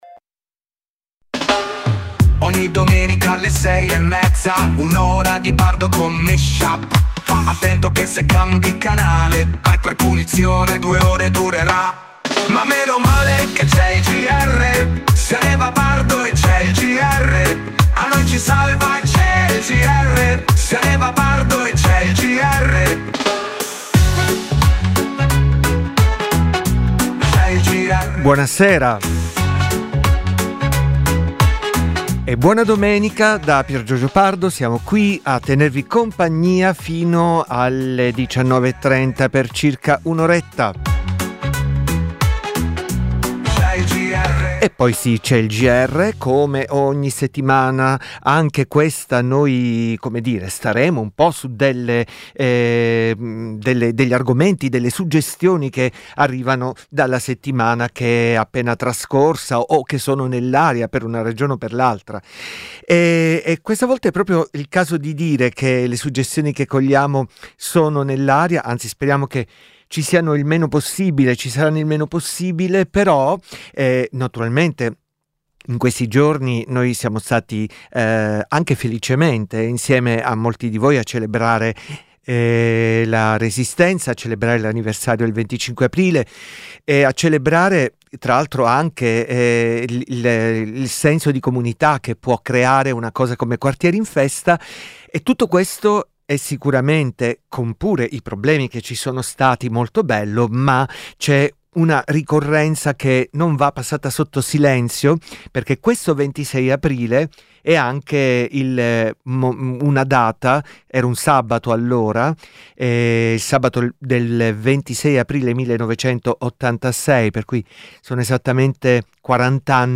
dj set tematico di musica e parole